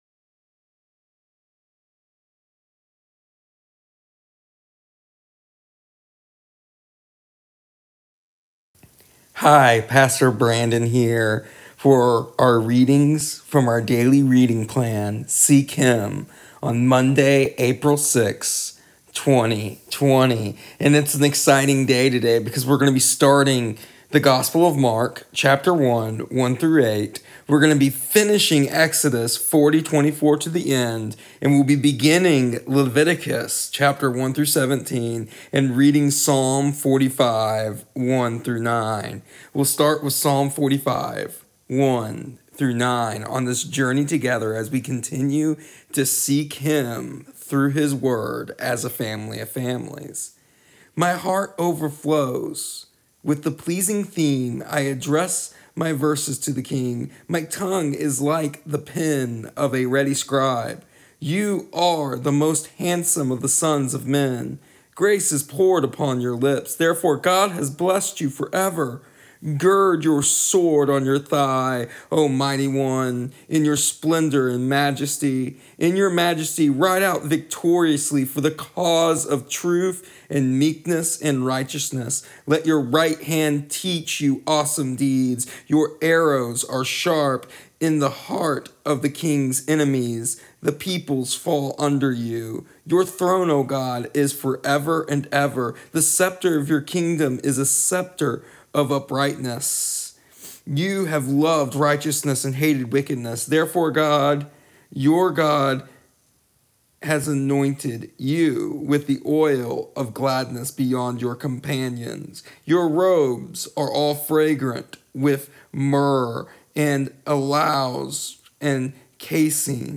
Today in our daily audio readings and devotional we finish the book of Exodus. Start the books of Mark and Leviticus, and discuss the transition that takes place from Exodus to Leviticus.